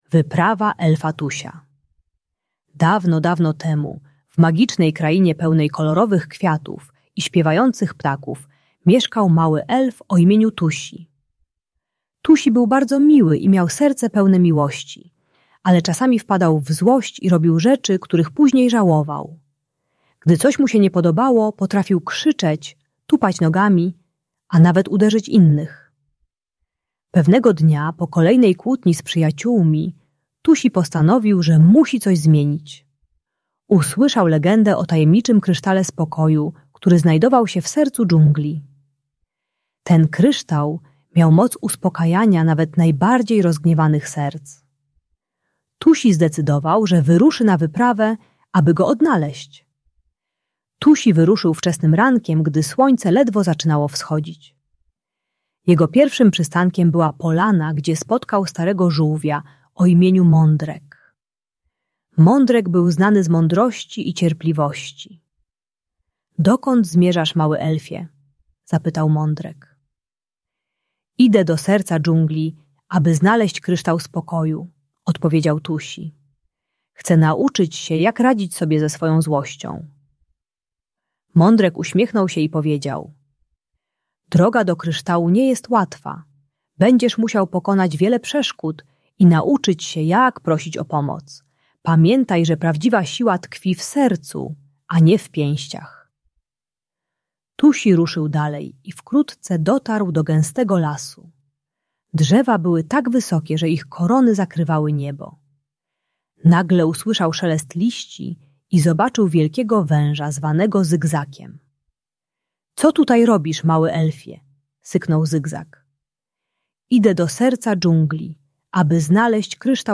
Wyprawa Elfa Tusia - Bunt i wybuchy złości | Audiobajka